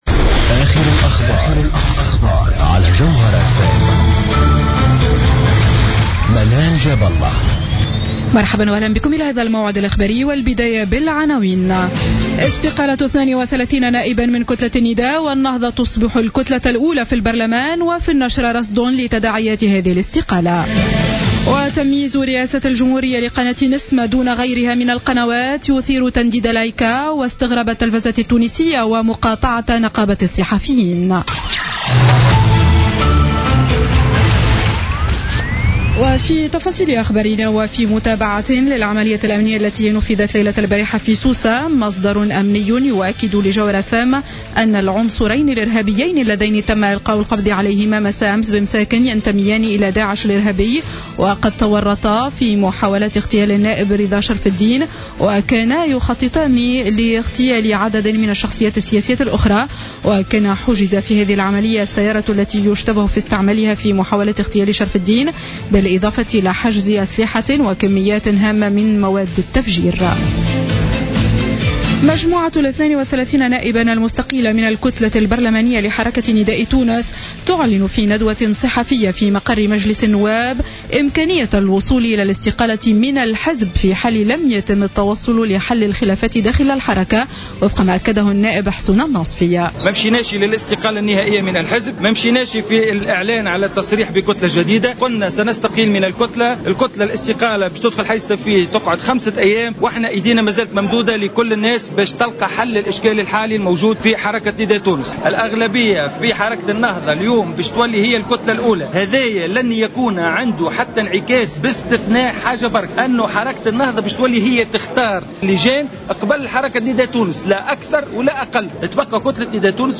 نشرة أخبار السابعة مساء ليوم الاثنين 9 نوفمبر 2015